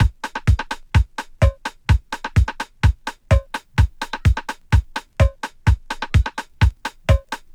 I was warping a simple drum track I recorded from vinyl and there was so much squeaky artifact noise in it that I thought there must have been interference when I recorded it. No, it was just Complex mode doing it’s thing.
Same track using Complex warp mode
warp-complex.wav